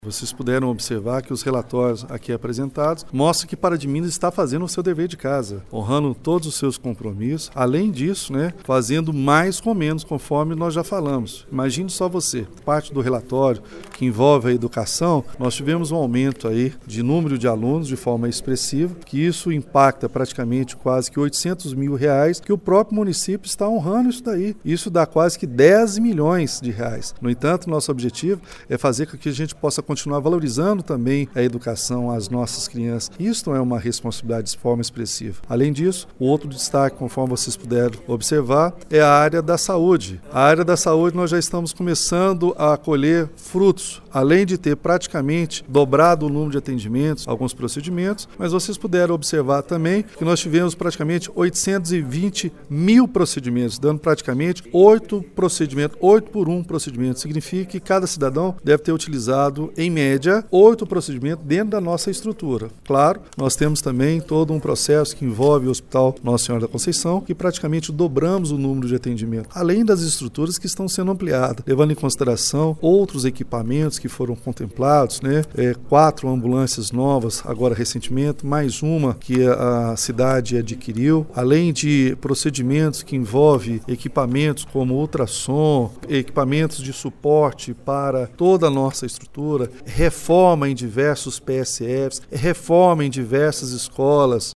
A Prefeitura de Pará de Minas realizou na noite desta quarta-feira, 26 de setembro, no plenário da Câmara Municipal, audiência pública para prestação de contas referente ao 2º quadrimestre de 2018.
De acordo com o prefeito Elias Diniz (PSD) os números do relatório são frios e ao mesmo tempo positivos. Disse também que o município está fazendo seu dever de casa por cortar gastos e investir mais: